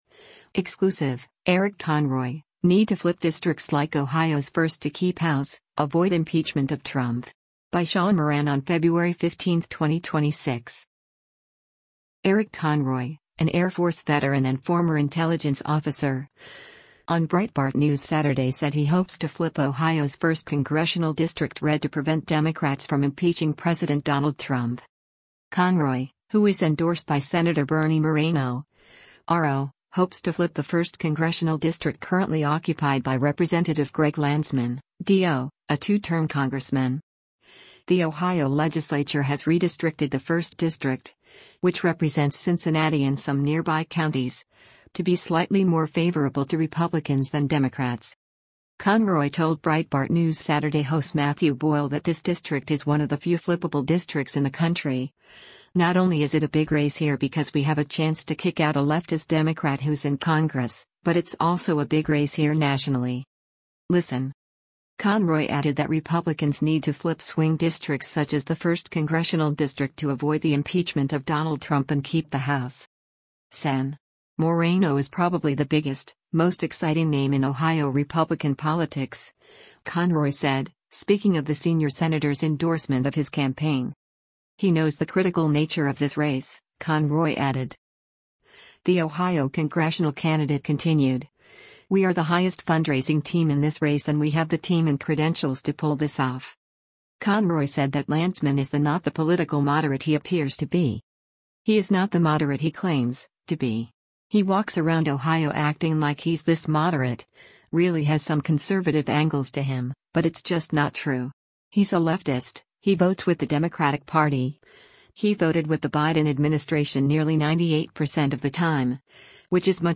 Breitbart News Saturday airs on SiriusXM Patriot 125 from 10:00 a.m. to 1:00 p.m. Eastern.